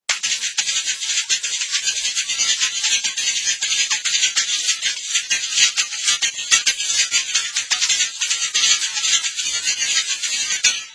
.רעשן ברזילאי
.נשמעת כמו תזמורת שלמה
ganza.wma